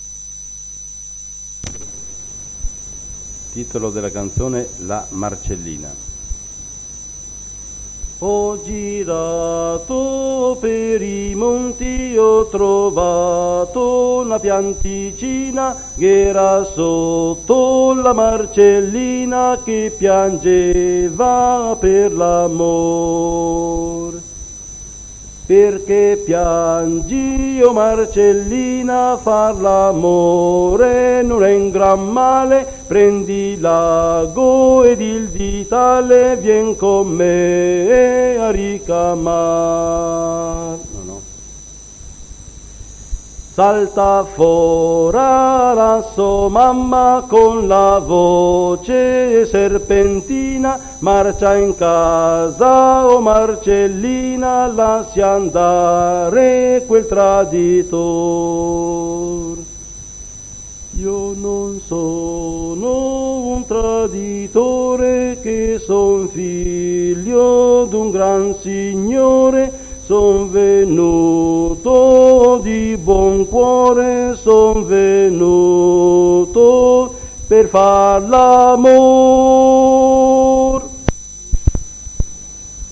Registrazioni di canti popolari effettuate presso la sede del Coro Genzianella di Condino. 11 settembre 1972. 1 bobina di nastro magnetico.